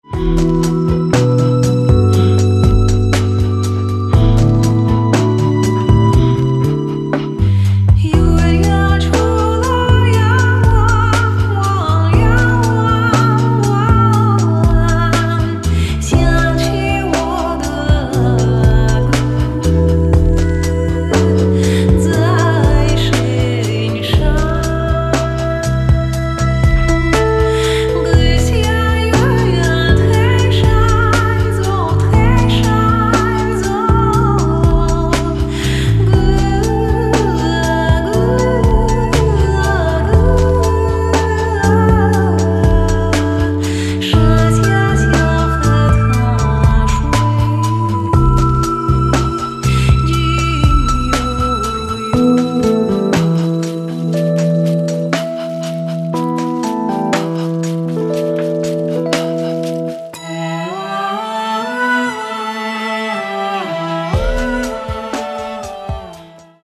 слова: китайська народна